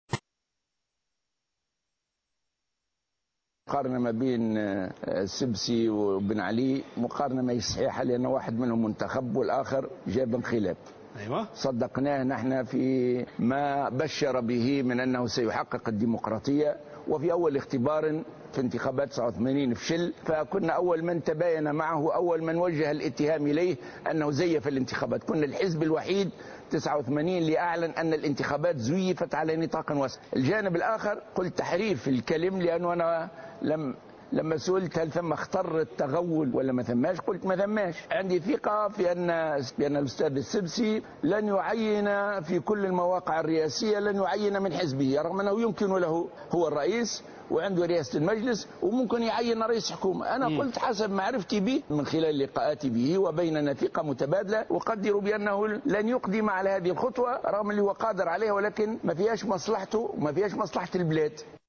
Dans une interview accordée à la chaine télévisée Al Hiwar Attounsi, le leader du mouvement Ennahdha Rached Ghannouchi a considéré que Beji Caied Essebsi, nouvellement élu président de la République ne pouvait pas être comparé à Ben Ali le président déchu.